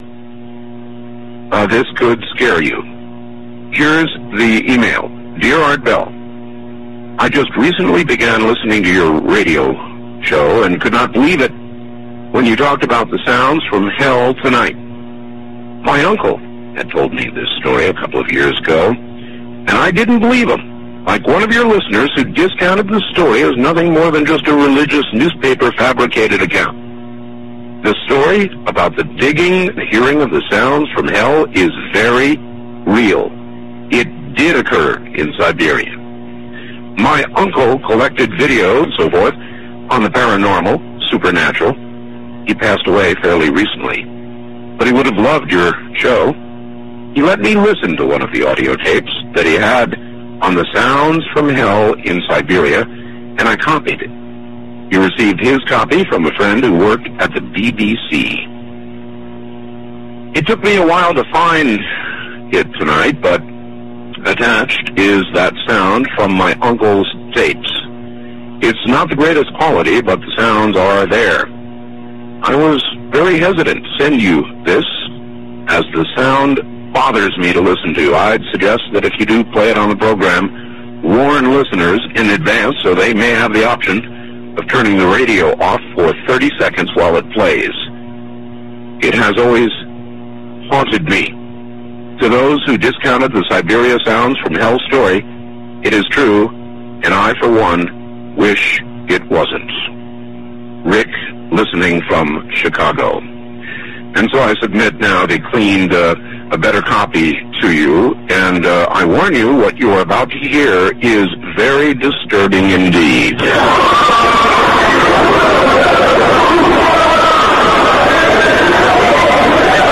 صدای فریاد هزاران هزار انسان!